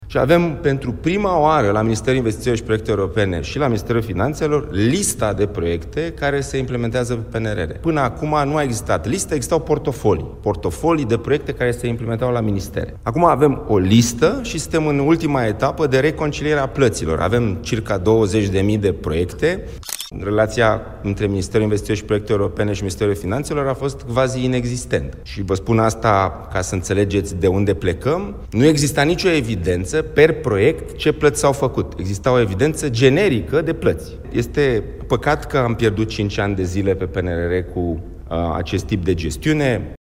Ministrul Proiectelor Europene, Dragoș Pîslaru: „Nu exista nicio evidență per proiect ce plăți s-au făcut. Exista o evidență generică de plăți”